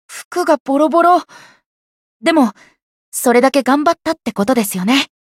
觉醒语音 服がボロボロ……。でも、それだけ頑張ったってことですよね 媒体文件:missionchara_voice_201.mp3